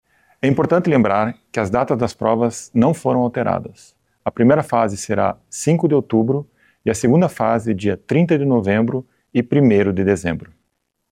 O reitor da universidade, Marcos Sunye, lembra que houve a prorrogação do prazo de inscrição, mas a data das provas não será alterada.